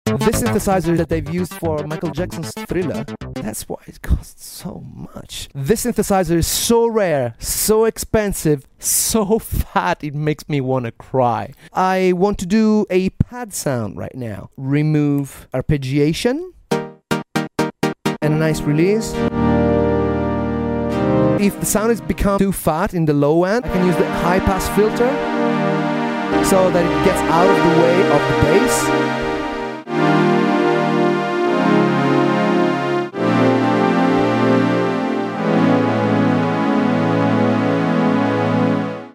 🔥 $35,000 synthesizer Roland Jupiter sound effects free download